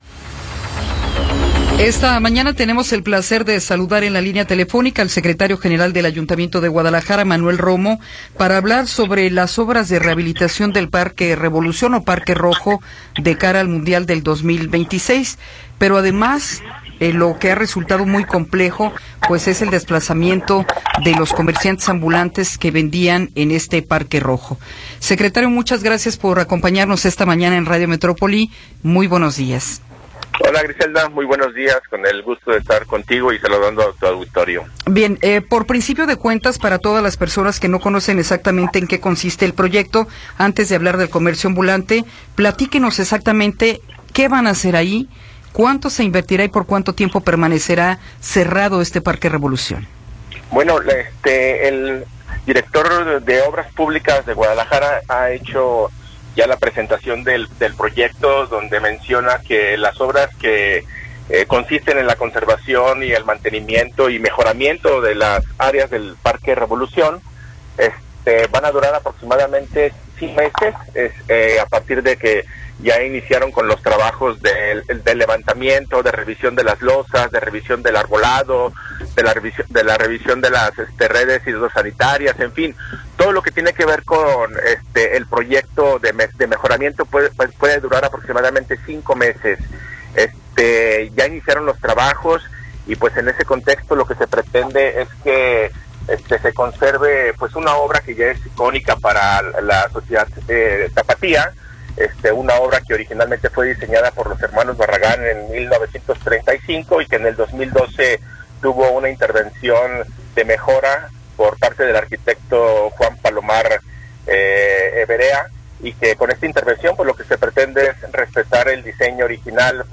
Entrevista con José Manuel Romo Parra | Notisistema